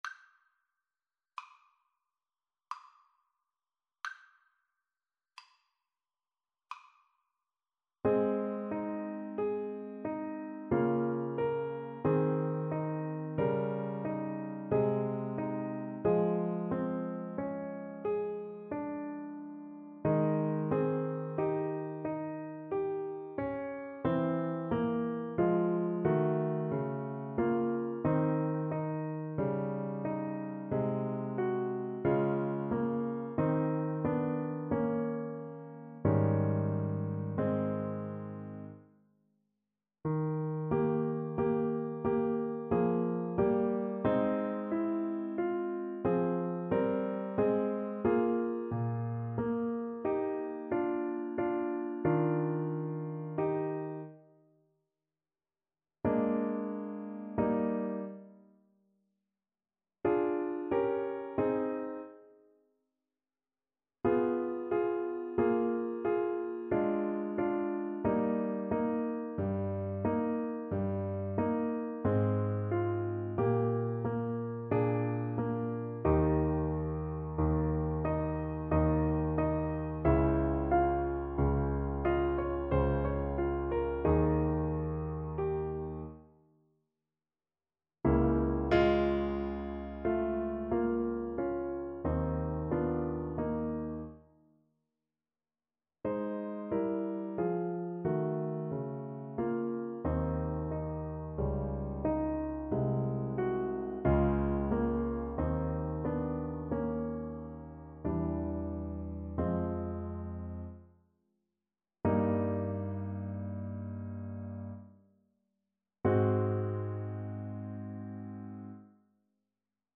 Ab major (Sounding Pitch) (View more Ab major Music for Flute )
Adagio =45
Classical (View more Classical Flute Music)